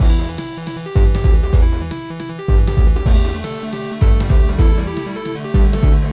Fast fun action techno loop 1
fast_techno_action_loop_0.ogg